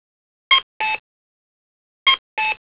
very notable sound signature of the space : a two-note audible
crosswalk signal for the visually impaired, which at the time was
the high note, right foot for the low note.
cuckoo.wav